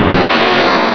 Cri de Massko dans Pokémon Rubis et Saphir.